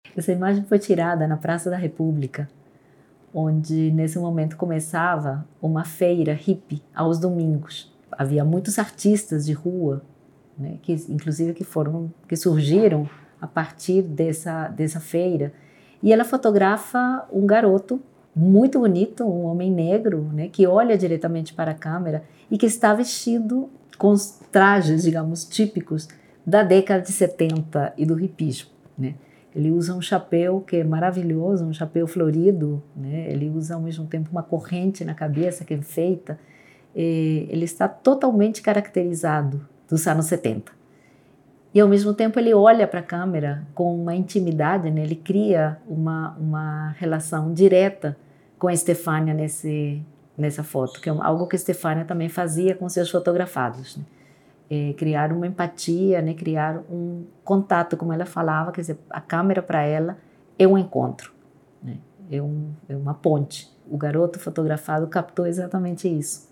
Faixa 2 - Comentário da curadoria
Faixa-02-comentario-curadoria-foto-moco-de-chapeu.mp3